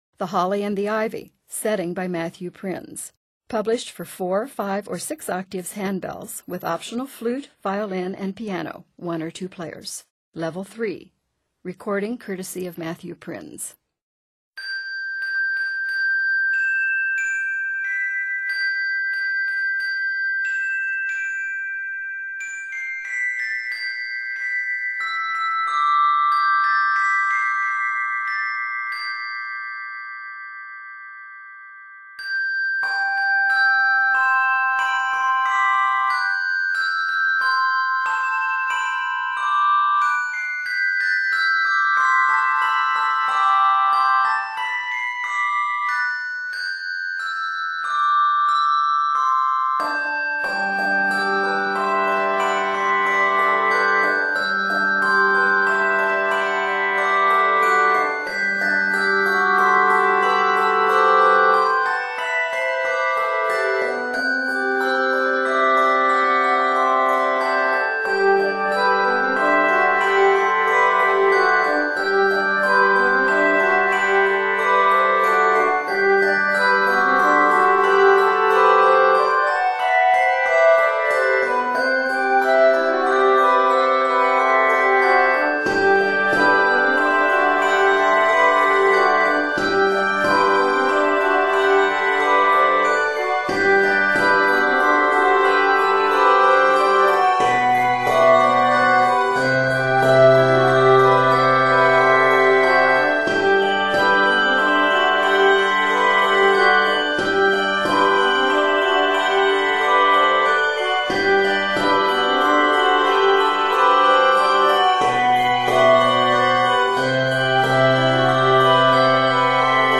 Scored in G Major, this piece is 108 measures.
Octaves: 4-6